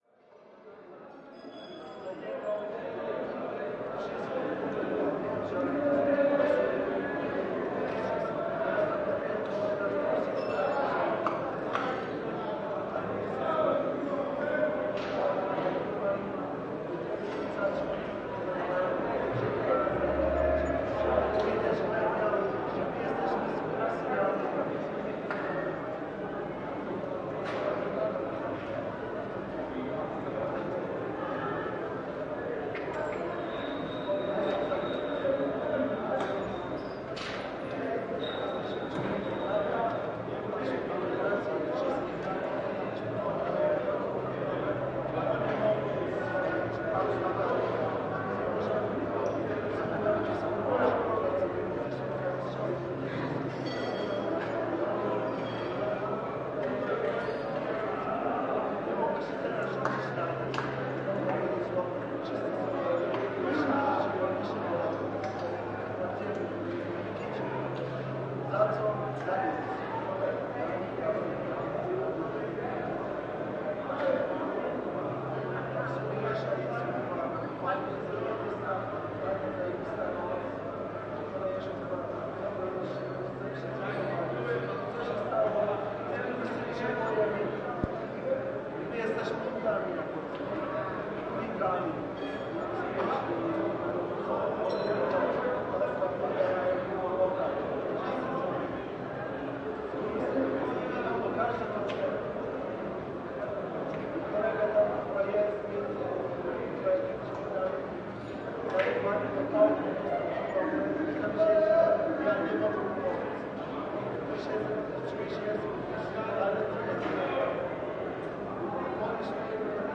街道 " 080815 lech poznan fans 001 - 声音 - 淘声网 - 免费音效素材资源|视频游戏配乐下载
08.08.2015：凌晨1点30分左右，在波兹南市中心的弗罗茨瓦夫斯卡街上。 Streetroit在当地足球队LechPoznań的最后一场比赛之后获得波兰冠军。